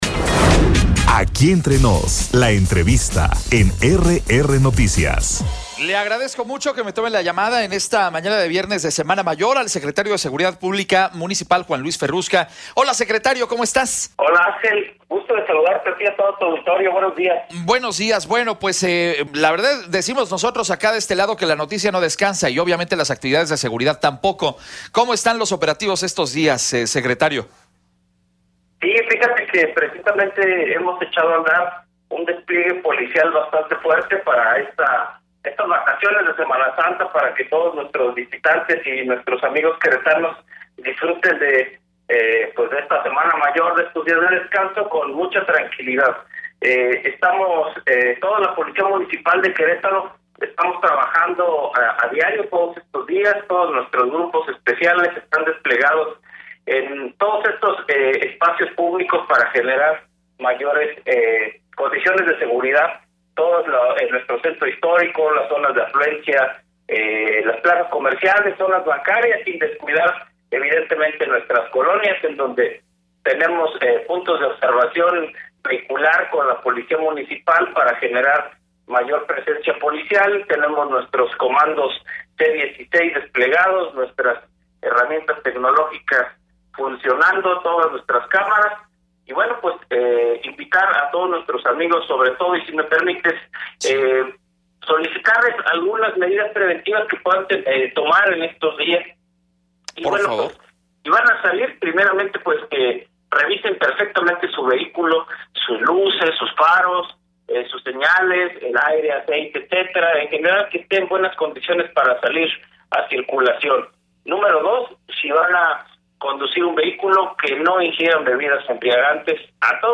La Entrevista: Juan Luis Ferrusca, Secretario de Seguridad Pública, Municipio de Querétaro
ENTREVISTA-FERRUSCA.mp3